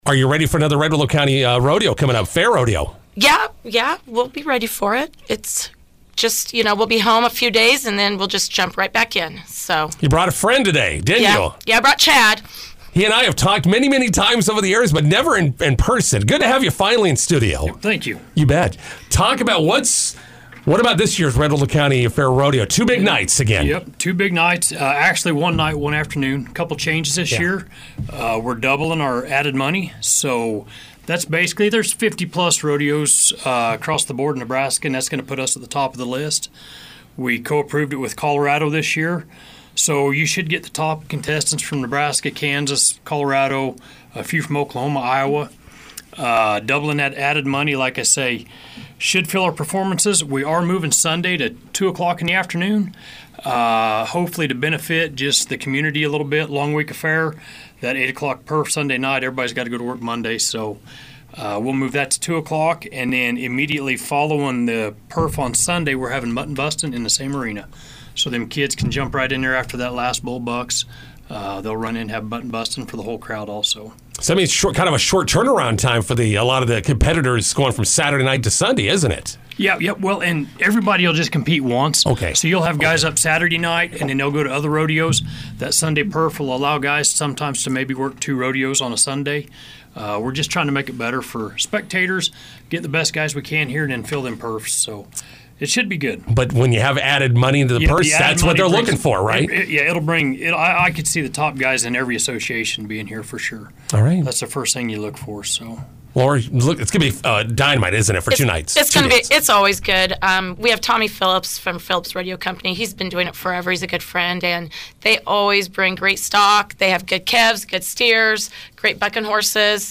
INTERVIEW: Red Willow County Fair Rodeo will feature many of the Midwest top stars in McCook July 26-27.